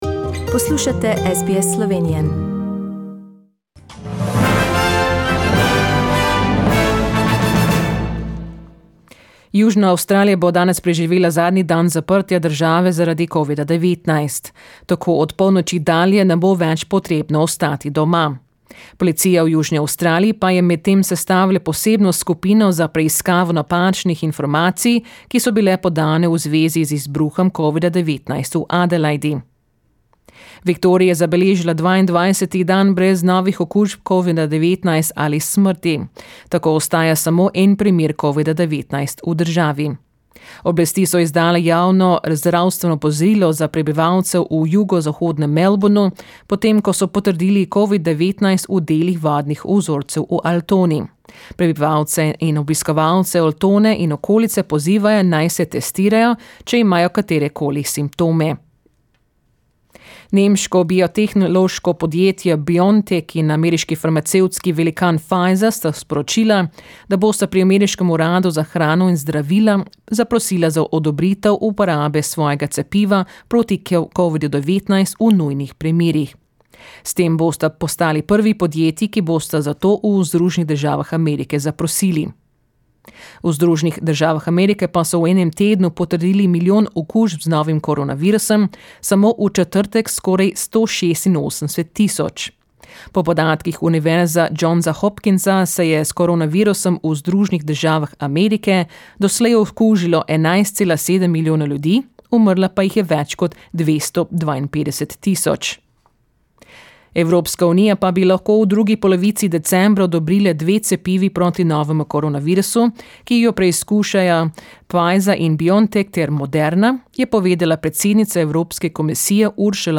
Today's news bulletin from the World, Slovenia and Australia in Slovenian.